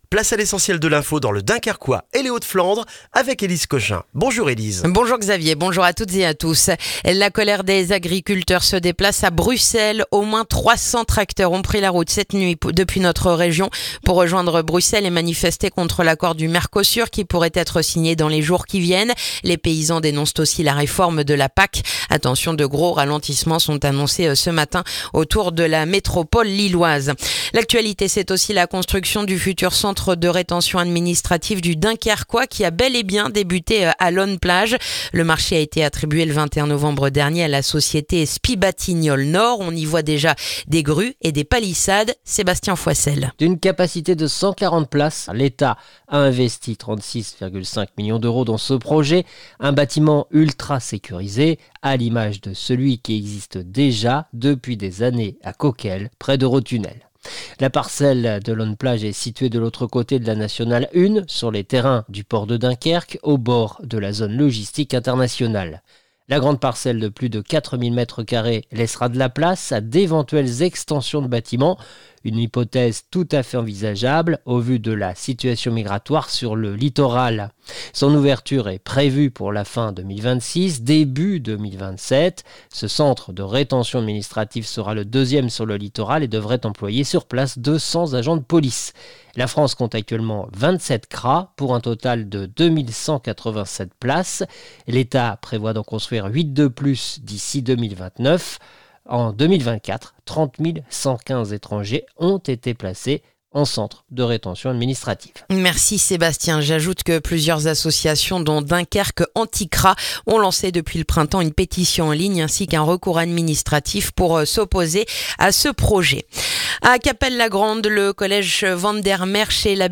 Le journal du jeudi 18 décembre dans le dunkerquois